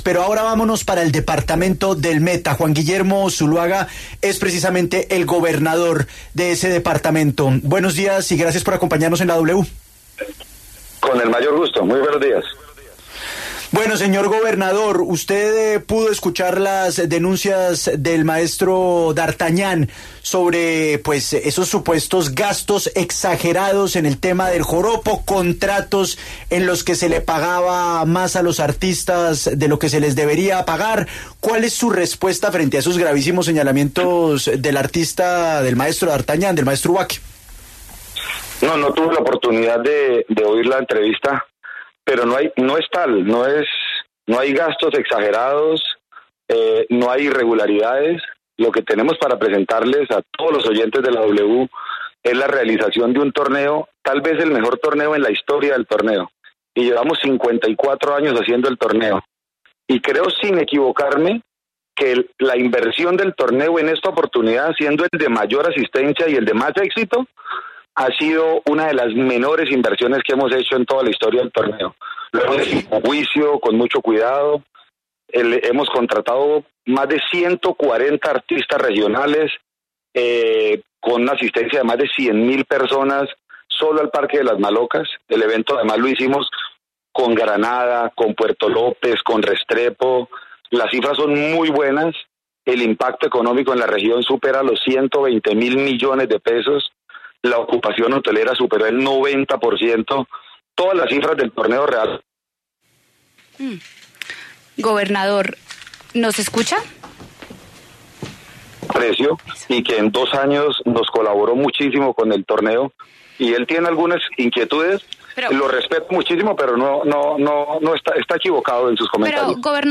Ante el anuncio de los inicios de diálogos de paz con el ELN, el gobernador del Meta, Juan Guillermo Zuluaga, en entrevista con 6AM enfatizó en la necesidad de que los grupos armados demuestren que tienen voluntad porque en algunas zonas se sigue recrudeciendo el tema de extorsiones, asesinatos a líderes sociales, incineración de buses y camiones, entre otros.